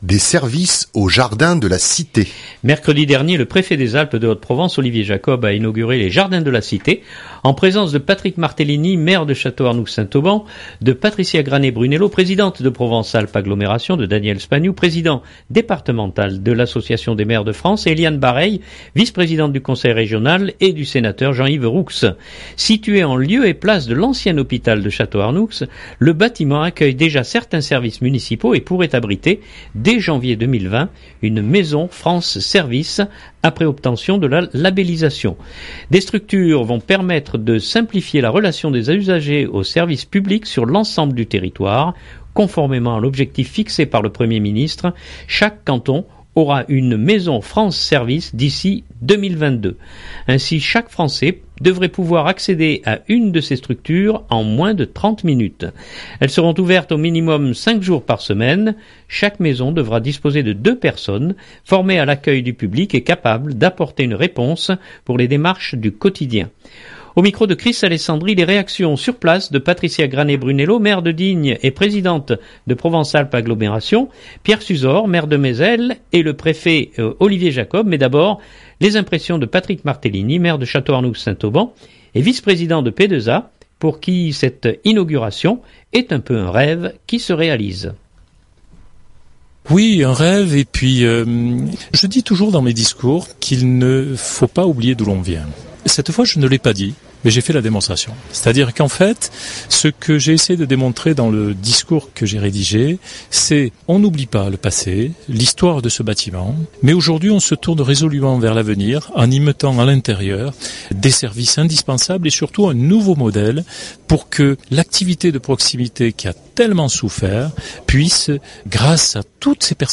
les réactions sur place de Patricia Granet-Brunello, maire de Digne et présidente de Provence Alpes Agglomération, Pierre Suzor, maire de Mézel et le préfet Olivier Jacob. Mais d’abord, les impressions de Patrick Martellini, Maire de Château-Arnoux-Saint-Auban et vice-président de PAA, pour qui cette inauguration est un peu un rêve qui se réalise.